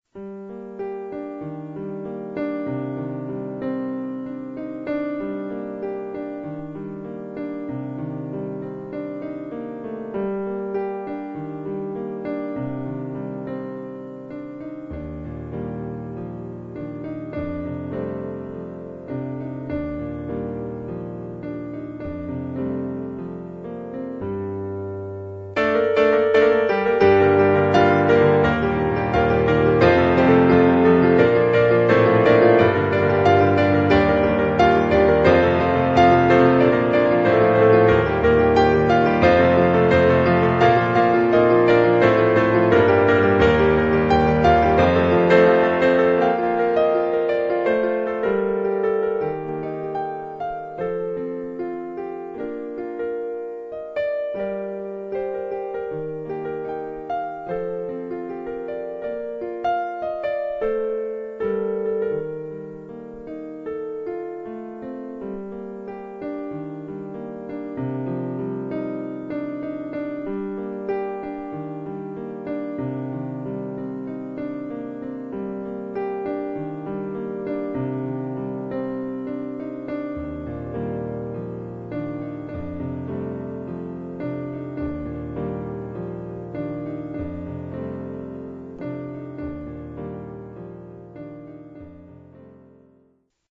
I'm not sure why -- the timing is atrocious, as usual, and there are million things I would change with it if I were to make it into a real song.
It has this beautiful lullaby sound to it.
In the loud part, I have my keyboard playing octaves, since I couldn't fill it up as much as I wanted with only two hands.